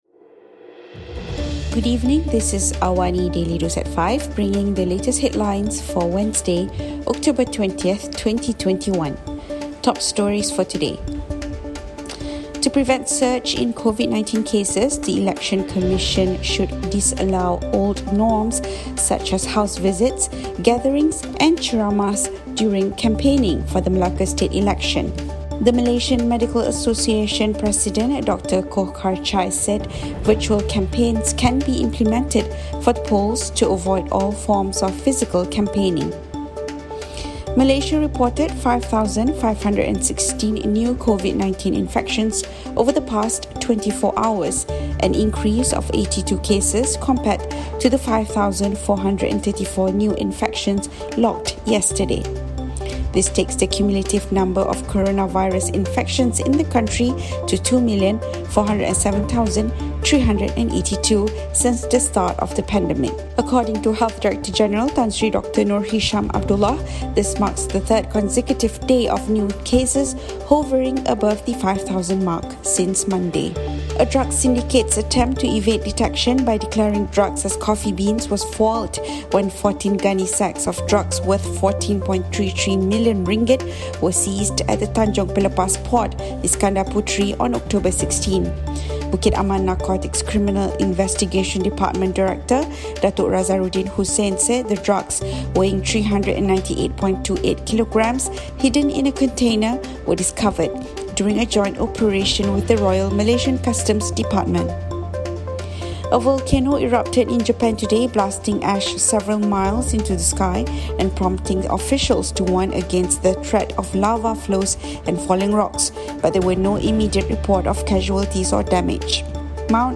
Also, A volcano erupted in Japan today, blasting ash several miles into the sky and prompting officials to warn against the threat of lava flows and falling rocks, but there were no immediate reports of casualties or damage. Listen to the top stories of the day, reporting from Astro AWANI newsroom — all in 3 minutes.